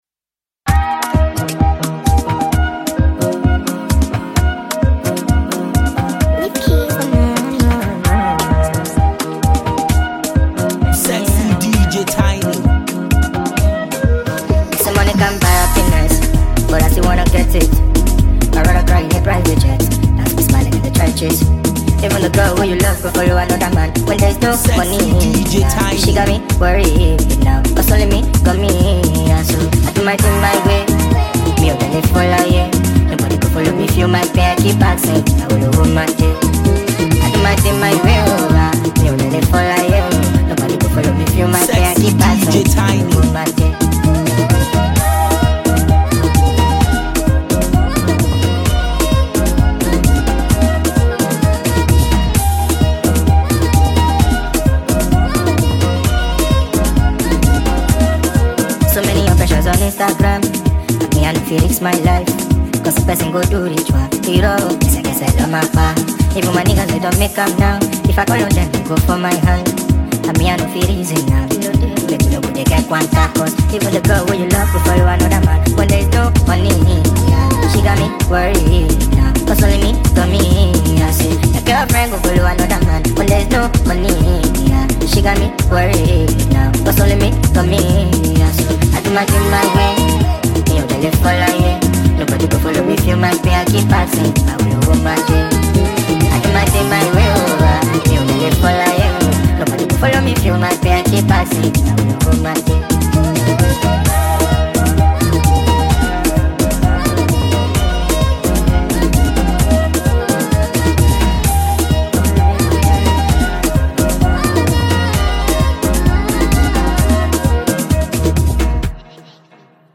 Enjoy this amazing studio track.